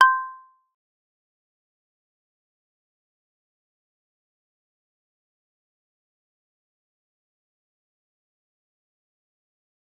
G_Kalimba-C6-f.wav